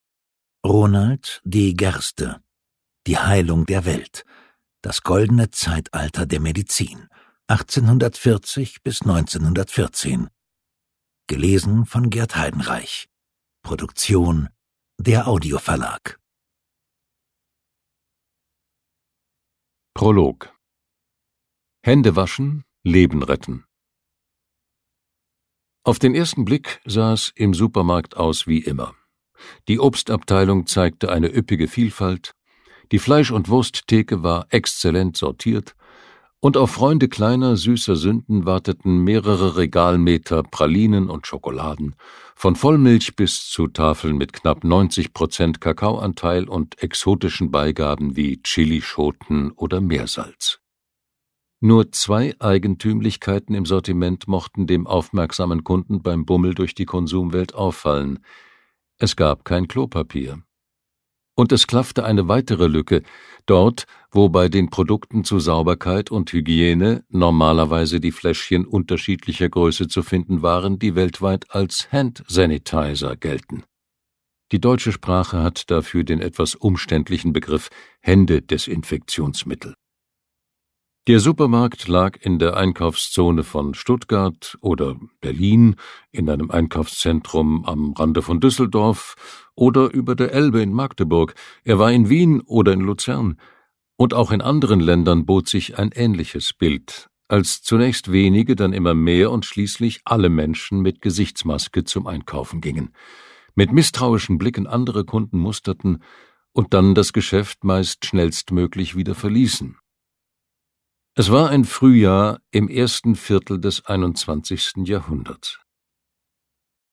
Hörbuch: Die Heilung der Welt.
Die Heilung der Welt. Das Goldene Zeitalter der Medizin 1840–1914 Lesung mit Gert Heidenreich
Gert Heidenreich (Sprecher)